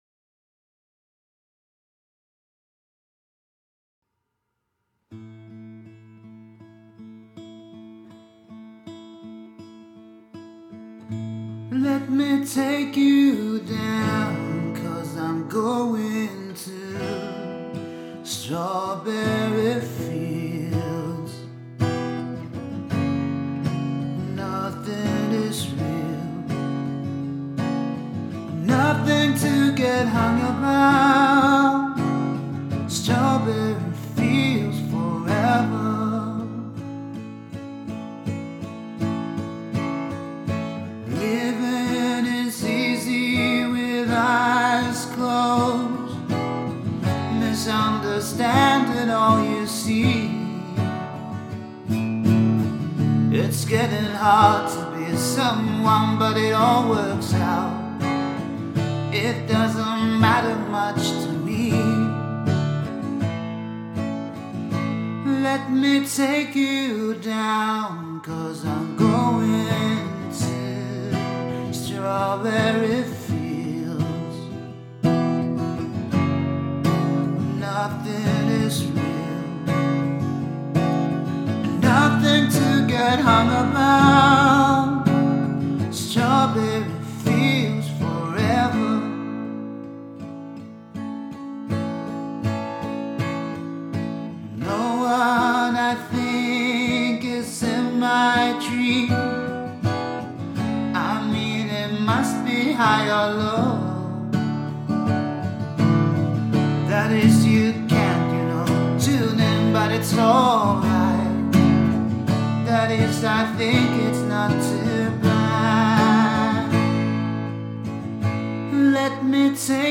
Trubaduuri
cover-kappaleita, omaa tuotantoa